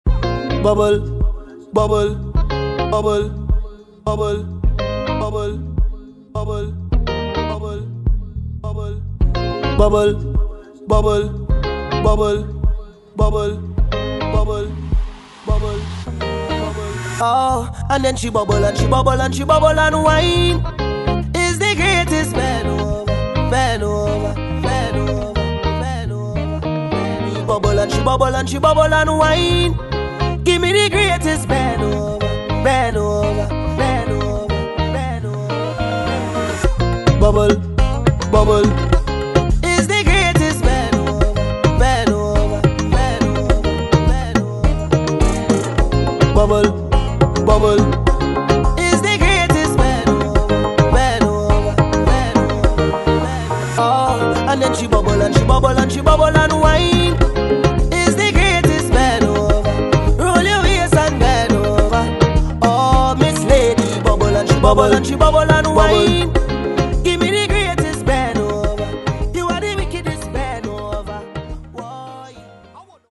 THIS IS A SINGLE TRACK REMIX (STR).
Soca